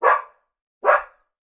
koira-4.mp3